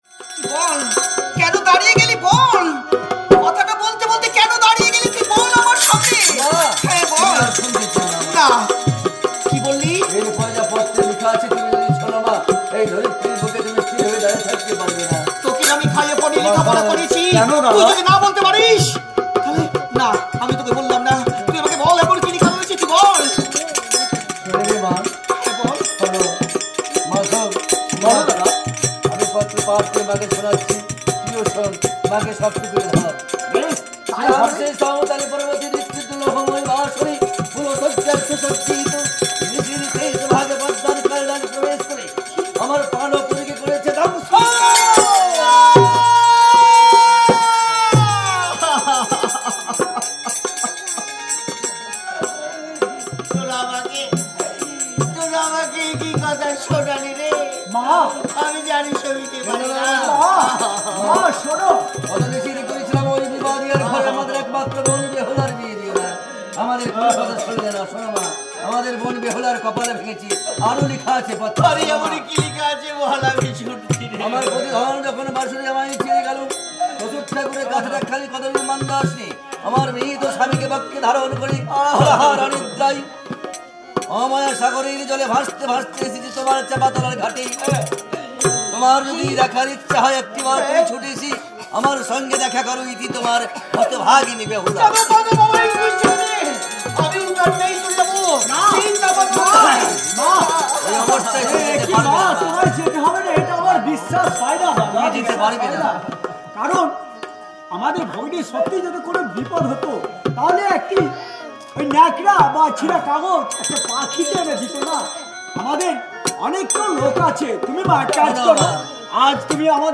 In both Barisal and Sylhet, the Manasa Mangal is sung from start to finish through the whole month of Sraban, mostly by women, as a community ritual.
murshidabad manasa.mp3